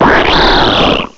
cry_not_greninja.aif